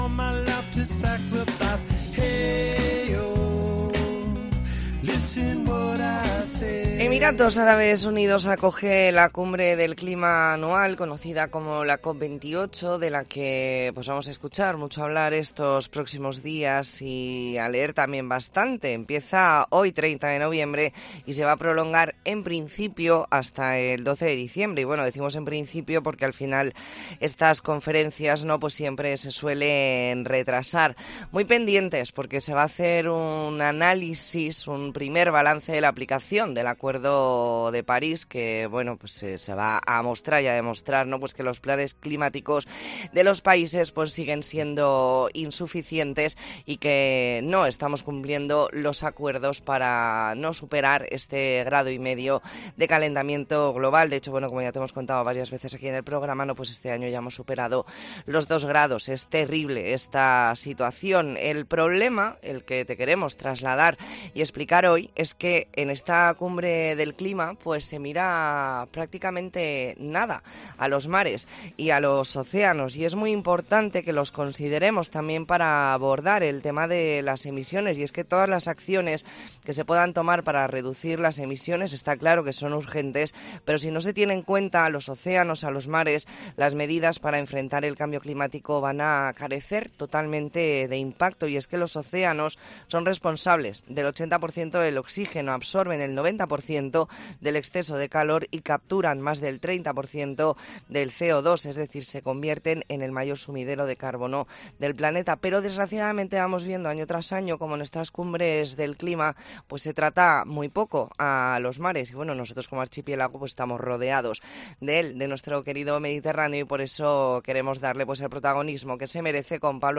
Entrevista e n La Mañ ana en COPE Más Mallorca, jueves 30 de noviembre de 2023.